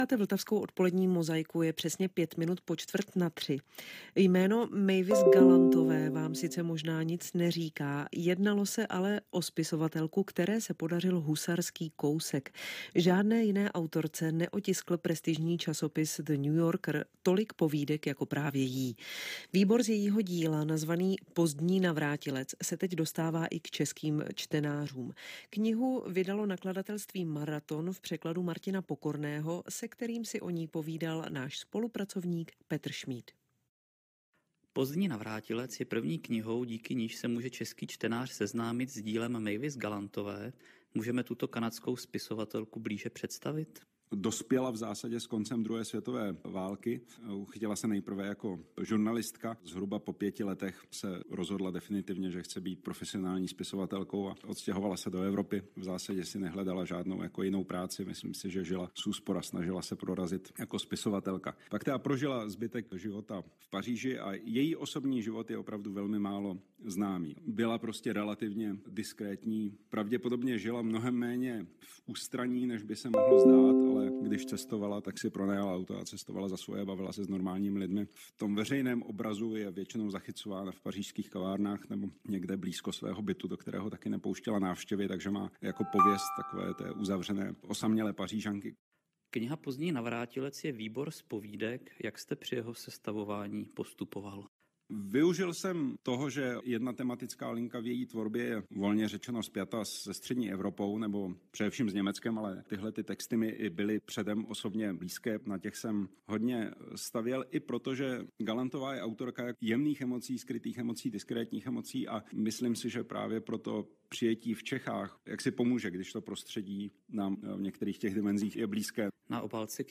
ROZHOVOR S PŘEKLADATELEM